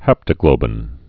(hăptə-glōbĭn)